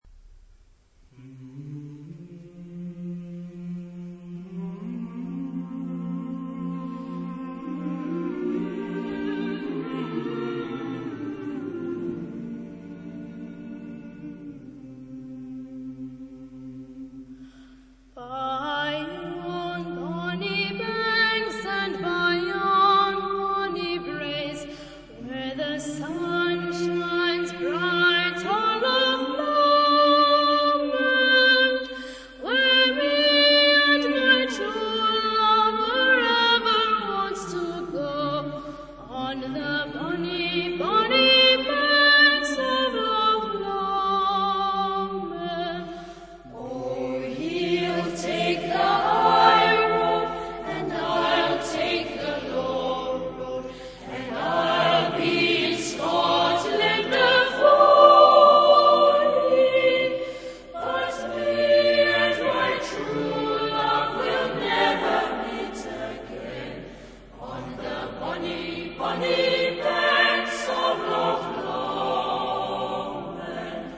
Genre-Style-Form: Secular ; Popular
Type of Choir: SATB (div)  (4 men OR mixed voices )
Tonality: F major
Origin: Scotland